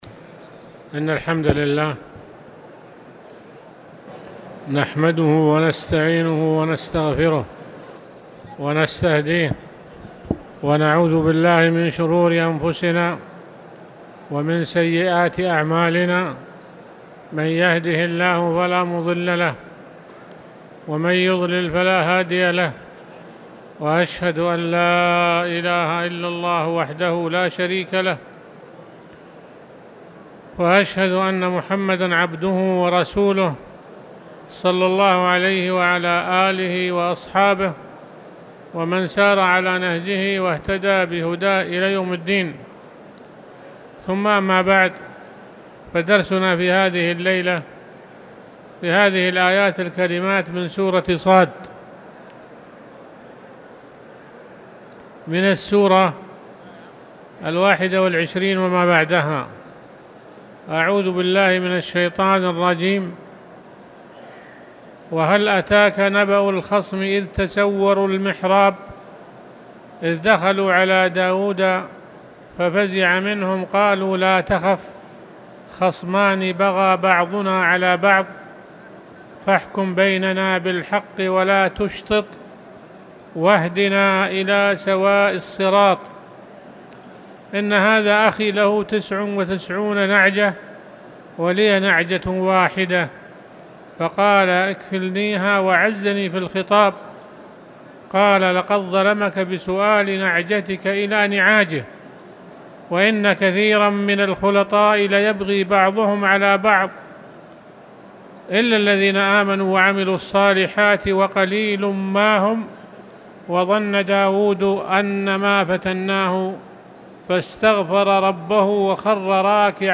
تاريخ النشر ٩ محرم ١٤٣٨ هـ المكان: المسجد الحرام الشيخ